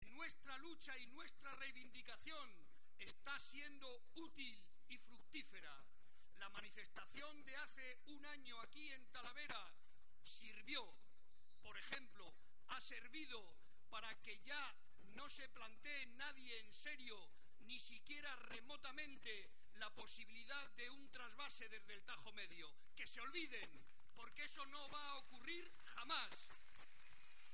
Barreda, que hoy participó en la concentración que se celebró en Talavera de la Reina (Toledo) en defensa del río Tajo, intervino ante las más de 15.000 personas que asistieron para recordar que siempre estará dispuesto, con la cabeza bien alta, “a defender nuestros intereses diciendo lo mismo en Toledo que en Madrid, en las Cortes regionales que en el Congreso de los Diputados, aquí y en Murcia, aquí y en Valencia”.